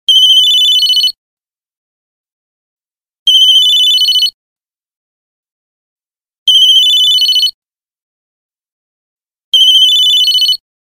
نغمة رنين هاتف
نغمة هاتف جميلة و سريعة عملية مناسبة لكل أنواع الهاتف الذكي